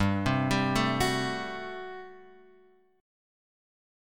GM7sus2sus4 chord {3 0 0 2 1 2} chord